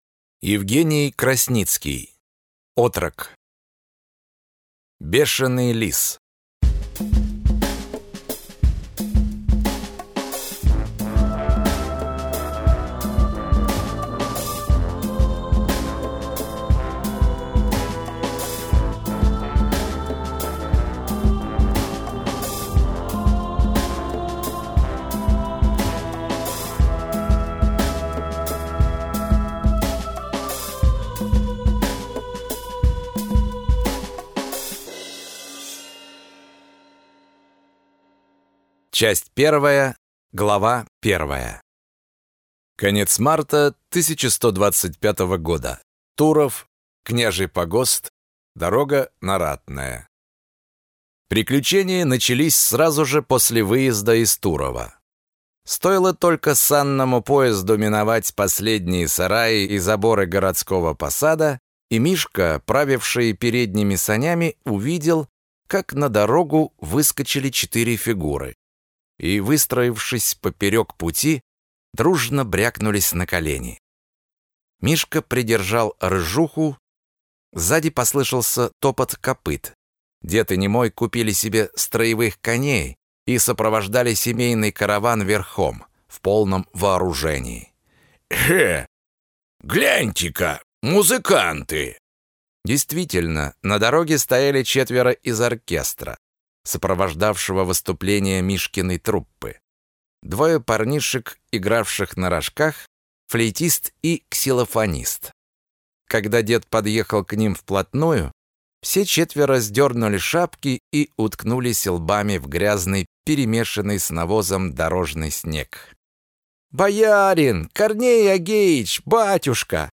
Аудиокнига Отрок. Бешеный Лис | Библиотека аудиокниг